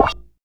SNARE.37.NEPT.wav